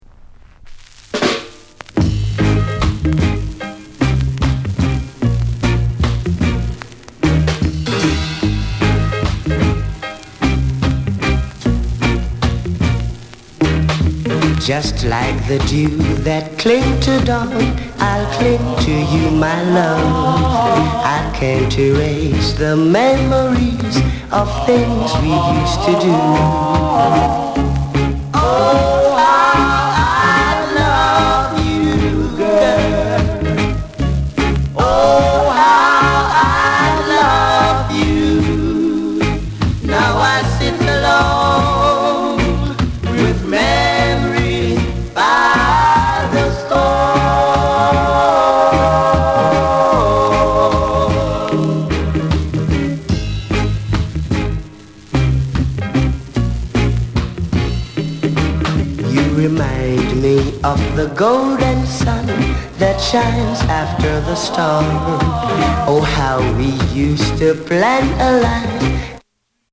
SOUND CONDITION A SIDE VG(OK)
NICE ROCKSTEADY